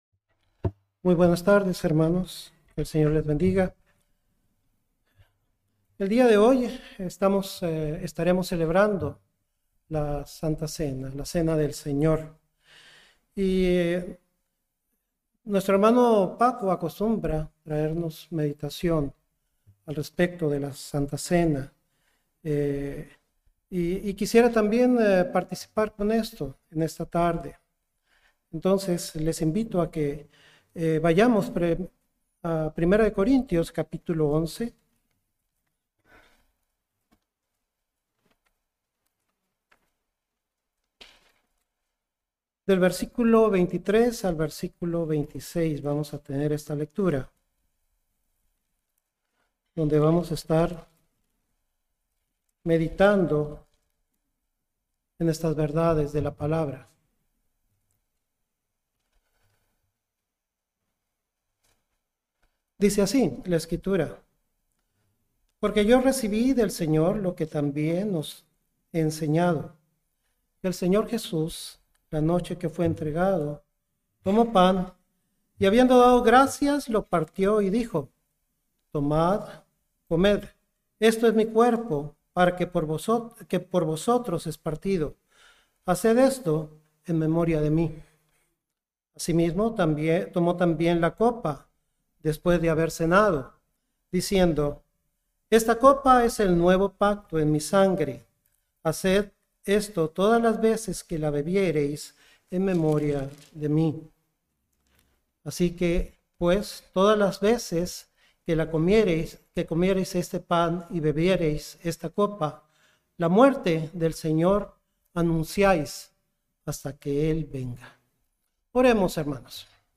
Serie de sermones Santa Cena